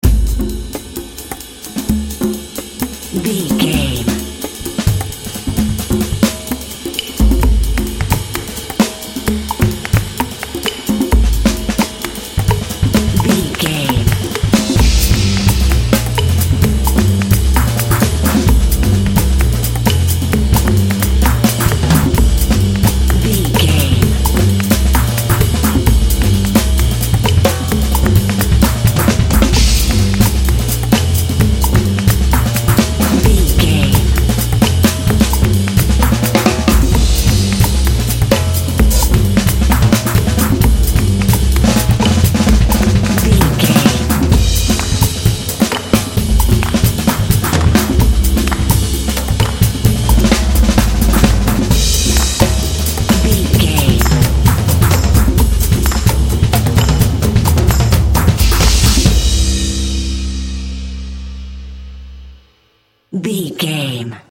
Mixolydian
groovy
percussion
double bass
jazz drums